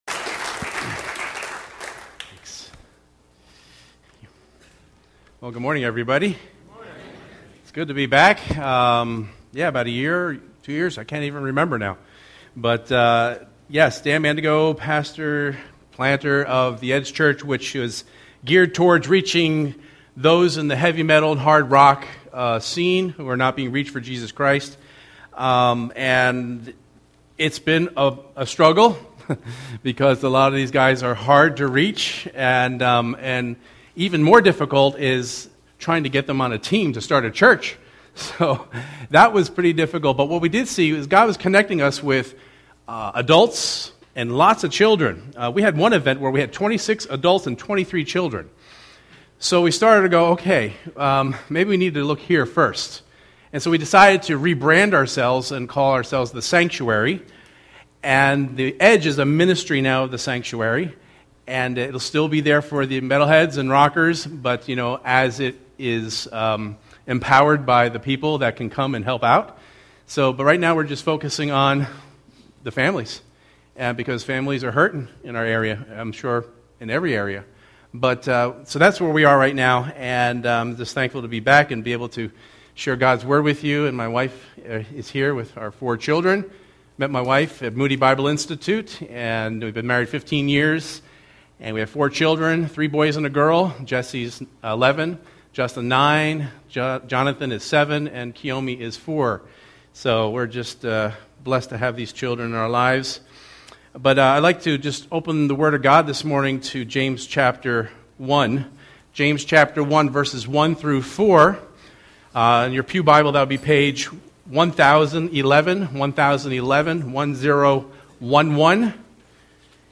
sermon82414.mp3